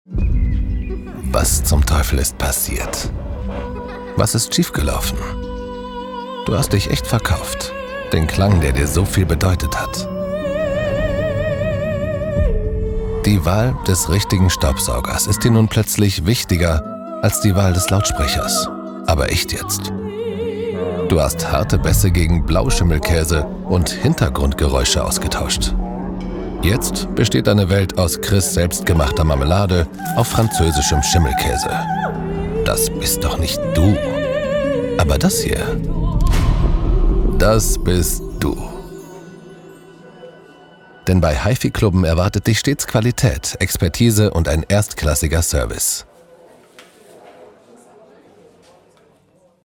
dunkel, sonor, souverän, markant, sehr variabel, hell, fein, zart
Mittel minus (25-45)
Commercial (Werbung), Presentation